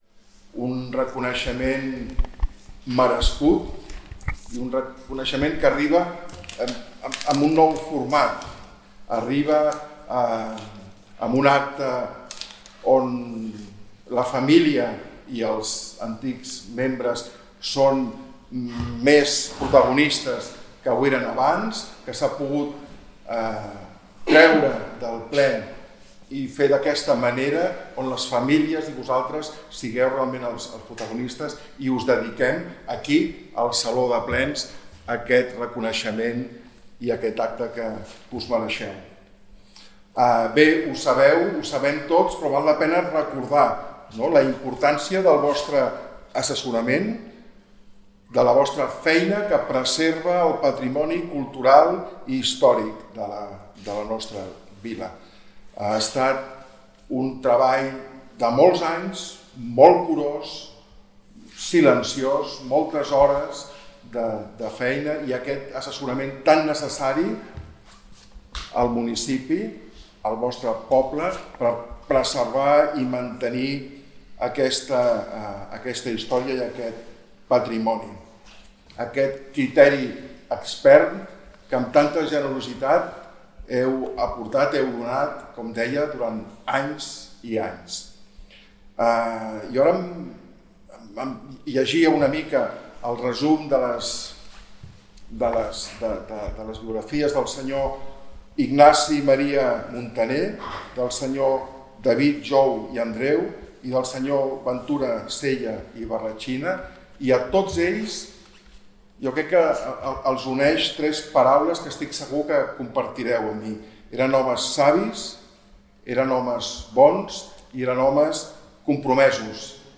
Us oferim l’acte íntegre que ha vingut precedit per la benvinguda del regidor de cultura, Albert Oliver-Rodés i ha tancat l’alcaldessa Aurora Carbonell, que ha entregat uns records en nom de l’Ajuntament.